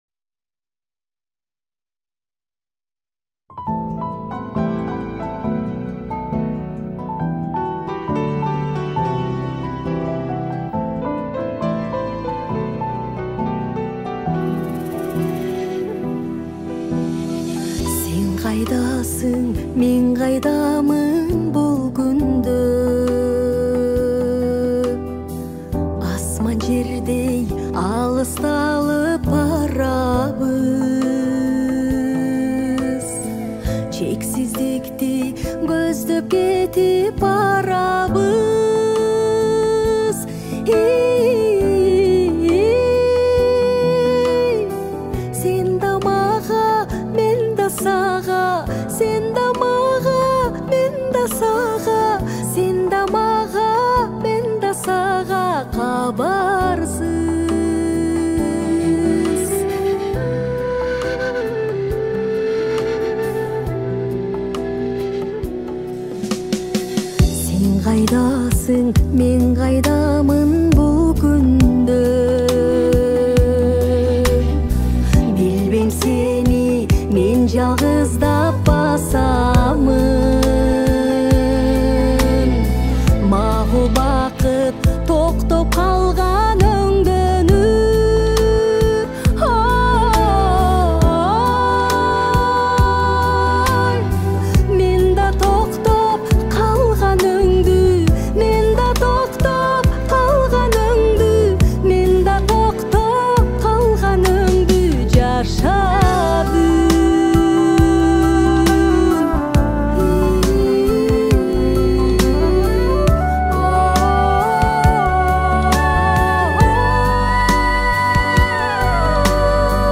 • Категория: Киргизские песни